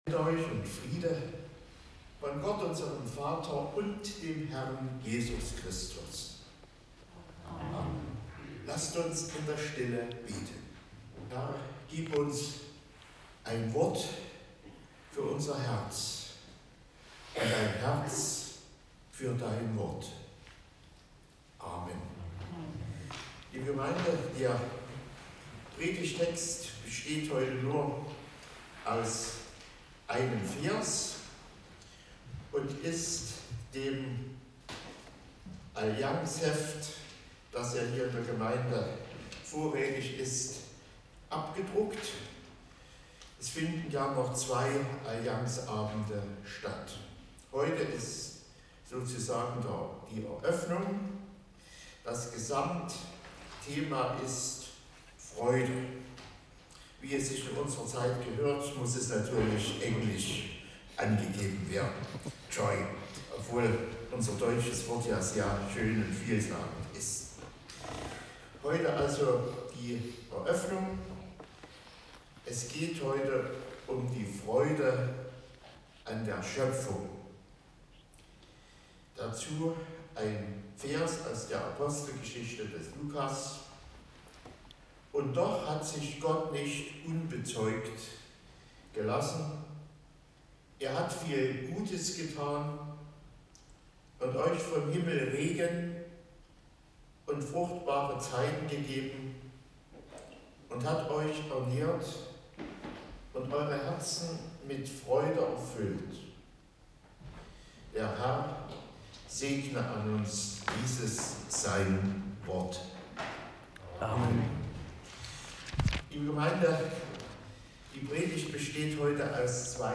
Gottesdienstart: Allianz-Gottesdienst